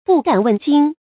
不敢问津 bù gǎn wèn jīn 成语解释 问津：询问渡口，问路。